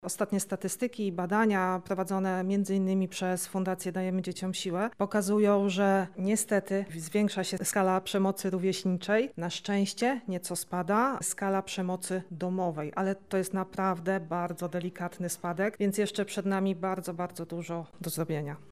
Dziś (02.10) w Szkole Podstawowej nr 2 im. Jana Kochanowskiego w Lublinie odbyła się uroczysta inauguracja kampanii społecznej „Dzieciństwo bez przemocy”, w której wzięli udział przedstawiciele Centrum Interwencji Kryzysowej oraz stowarzyszenia Sempre a Frente.